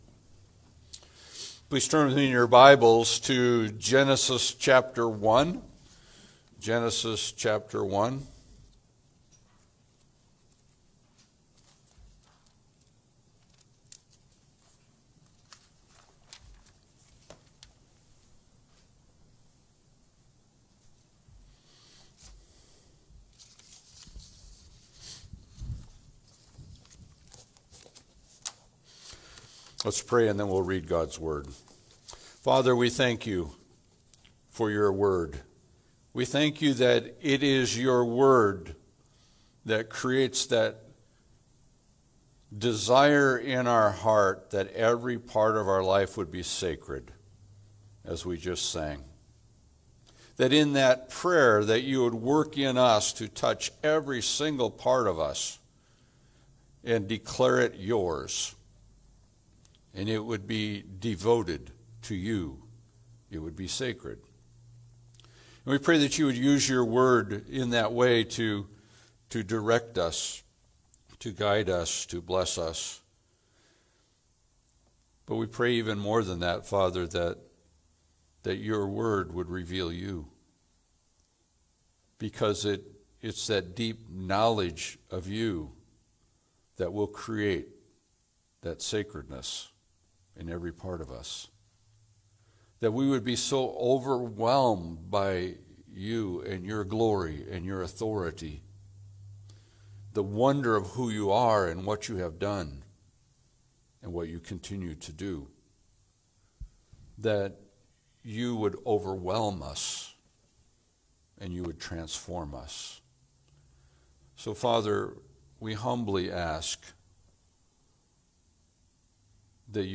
Passage: Genesis 1-2 Service Type: Sunday Service